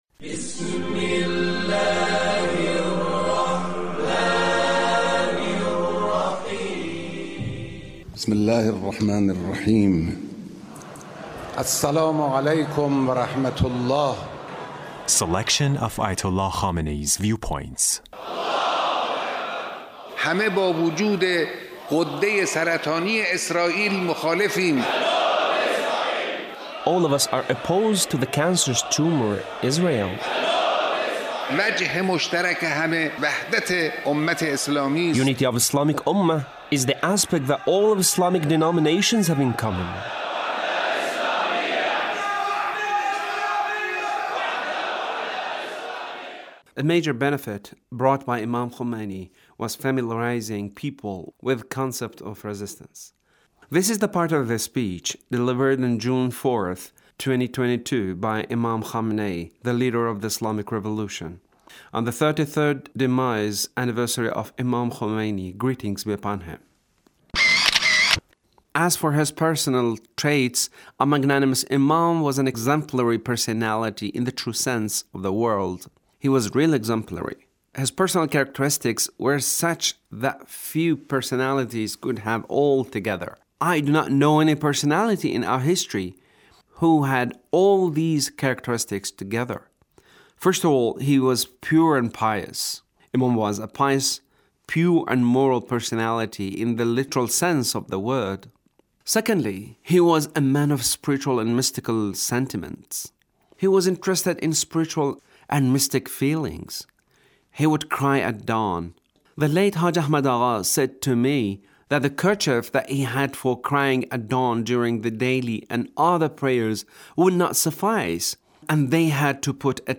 The Leader's speech on The Demise Anniversary of The Imam